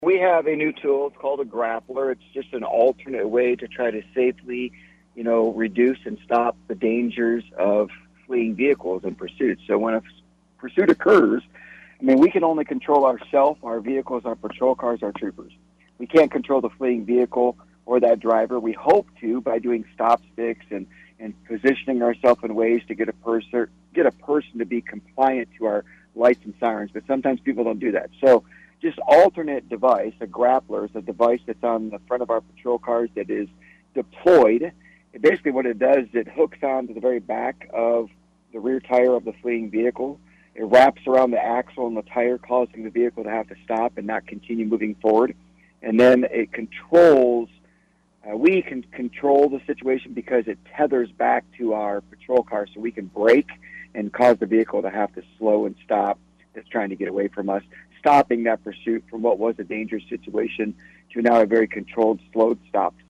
KSAL Morning News Extra